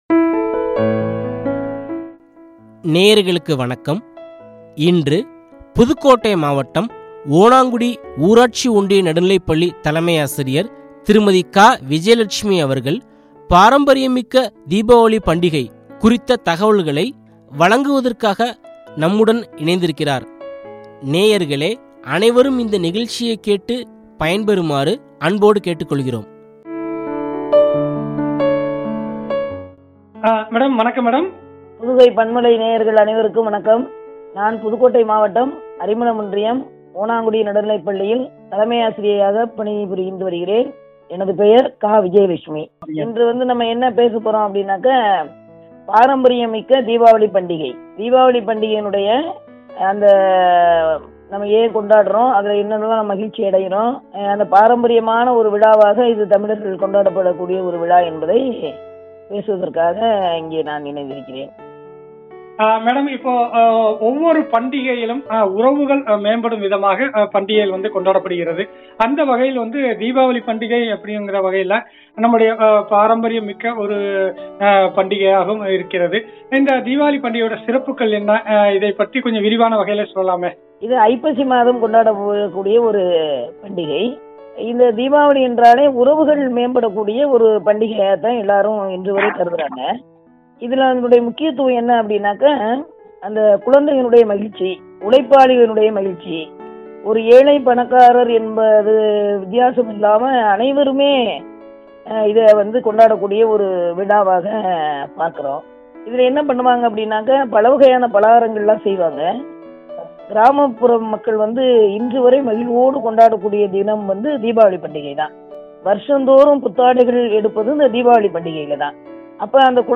தீபாவளி பண்டிகையும்” குறித்து வழங்கிய உரையாடல்.